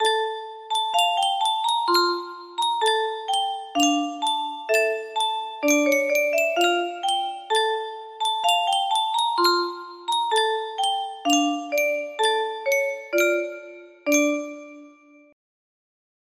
Yunsheng Music Box - Ukraine National Anthem 4177 music box melody
Full range 60